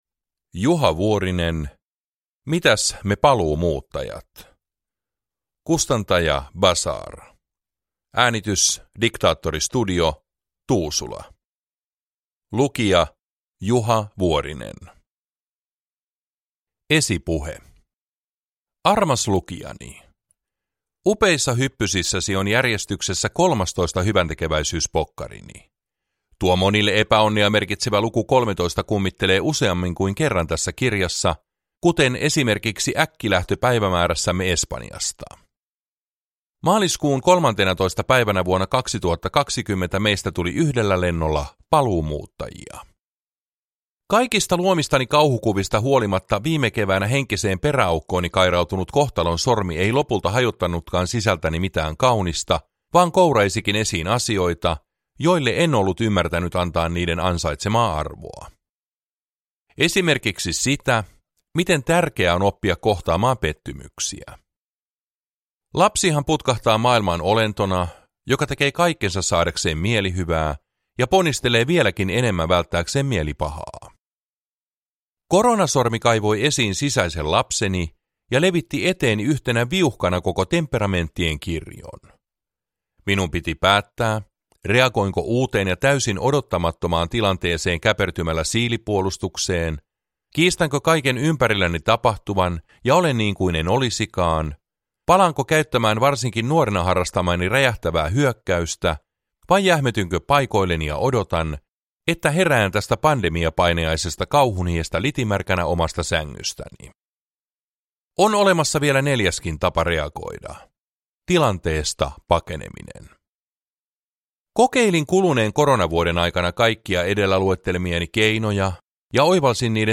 Mitäs me paluumuuttajat – Ljudbok
Uppläsare: Juha Vuorinen